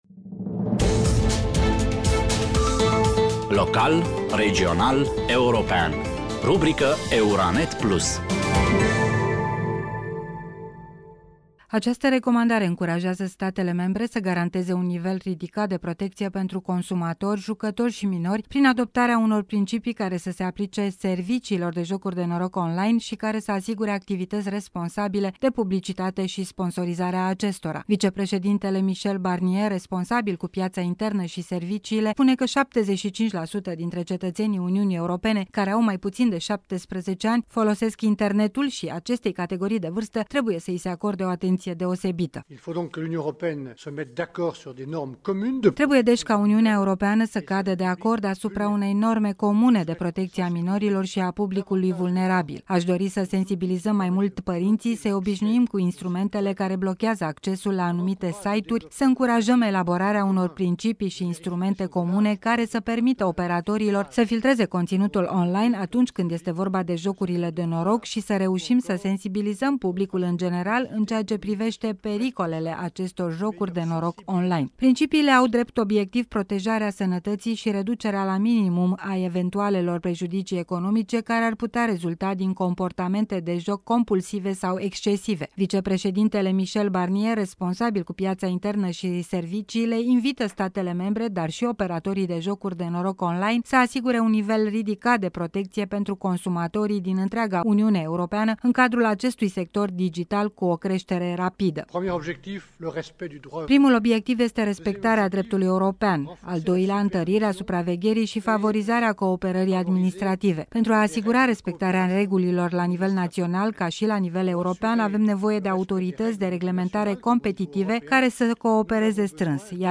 Vicepreședintele Michel Barnier, responsabil cu piața internă și serviciile invită statele membre, dar și operatorii de jocuri de noroc online, să asigure un nivel ridicat de protecție pentru consumatorii din întreaga UE în cadrul acestui sector digital cu creștere rapidă.